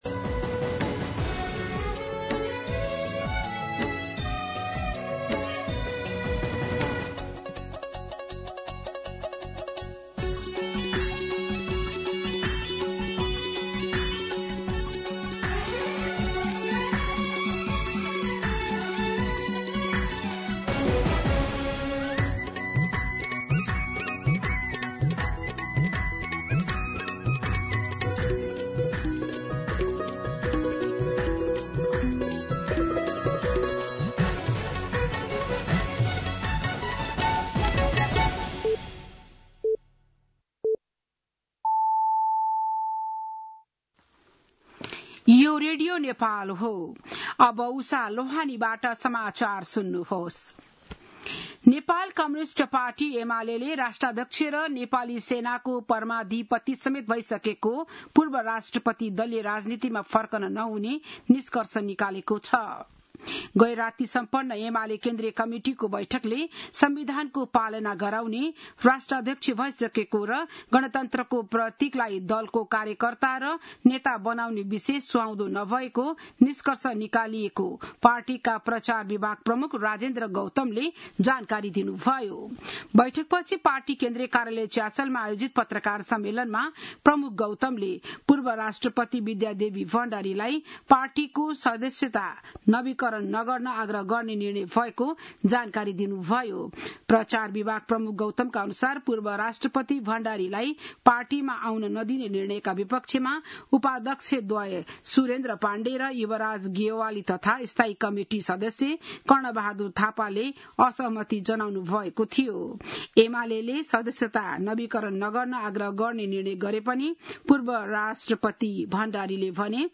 बिहान ११ बजेको नेपाली समाचार : ७ साउन , २०८२
11am-News-04-7.mp3